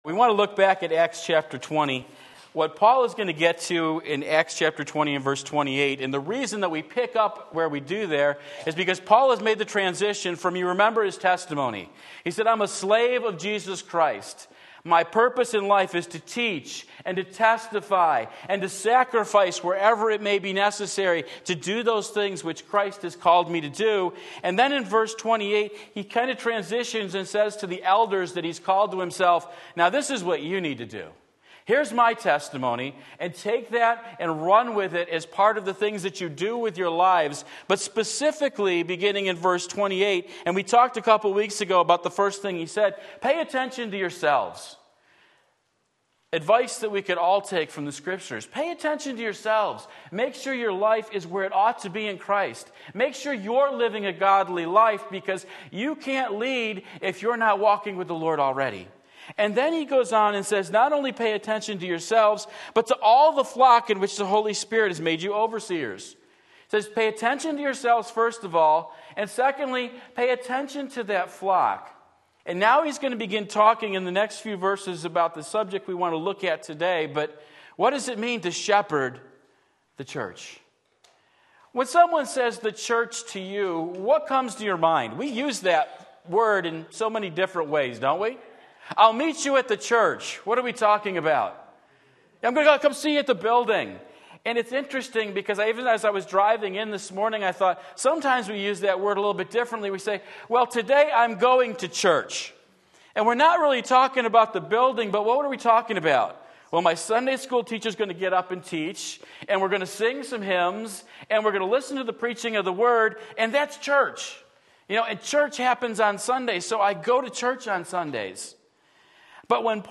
Sermon Link
Sunday Morning Service